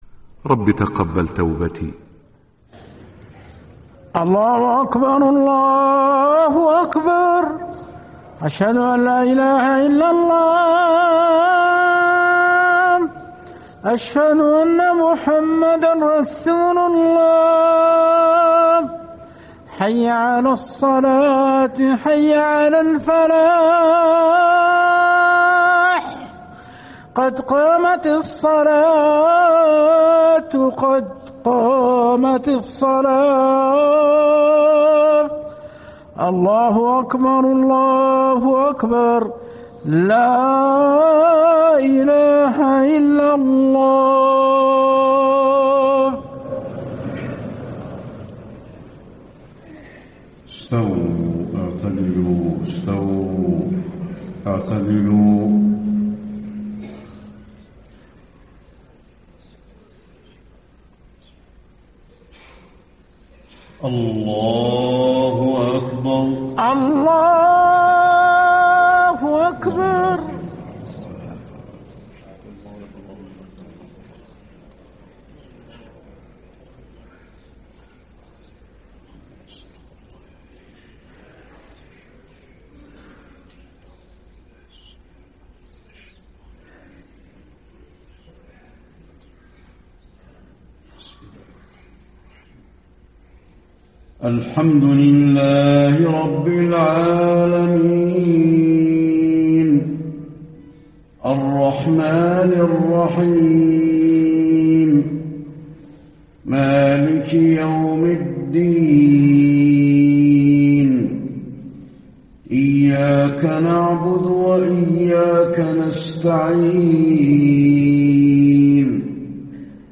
صلاة الفجر 5-2-1435 من سورة الإسراء > 1435 🕌 > الفروض - تلاوات الحرمين